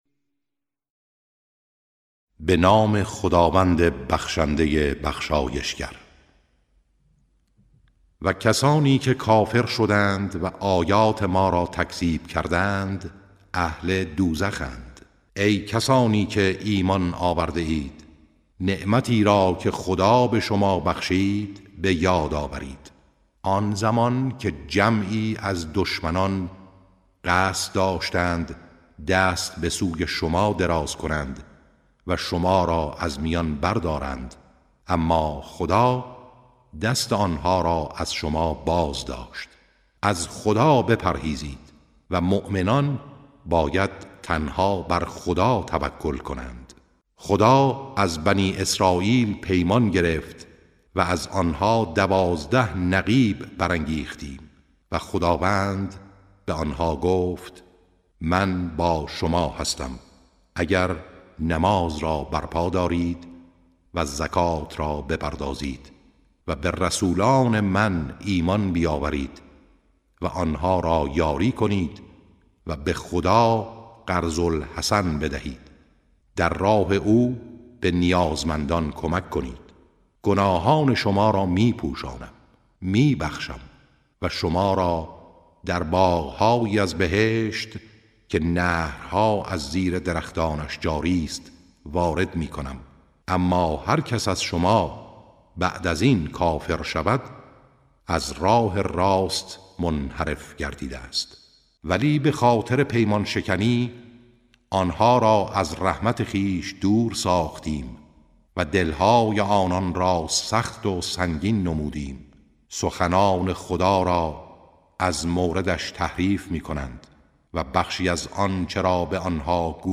ترجمه سوره( مائده)